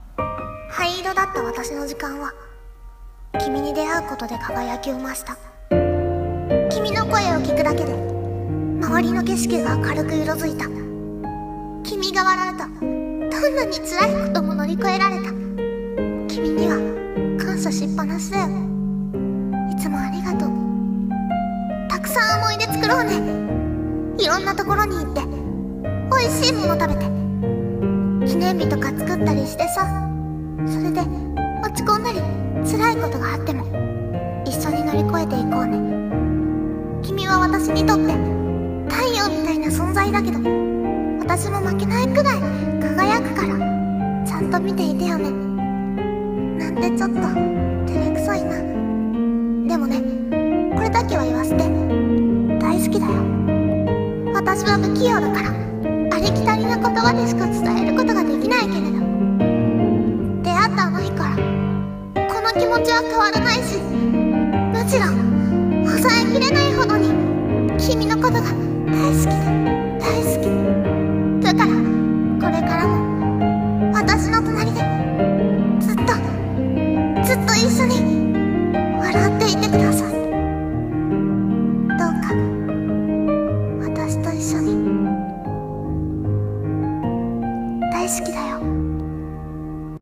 【声劇】君の存在【一人朗読】